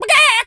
pgs/Assets/Audio/Animal_Impersonations/chicken_cluck_scream_long_02.wav
chicken_cluck_scream_long_02.wav